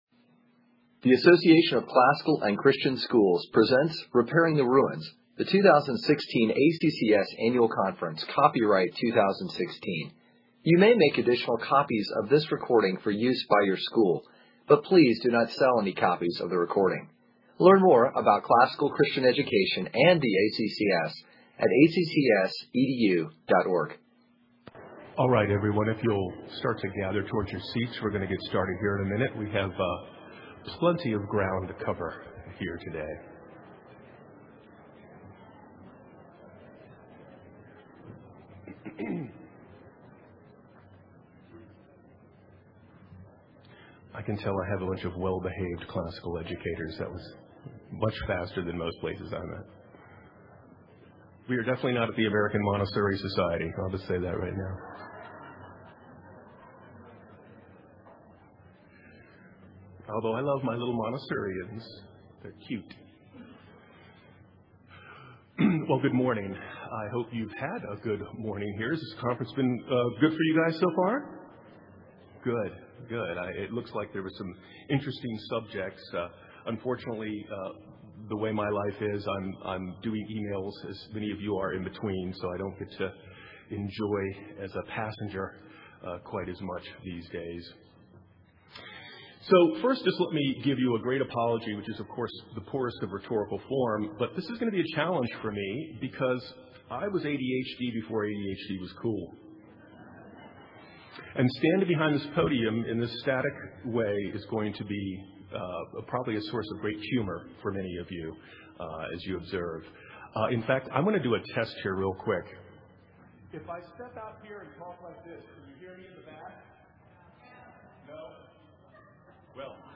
2016 Leaders Day Talk | 48:31:00 | Fundraising & Development, Marketing & Growth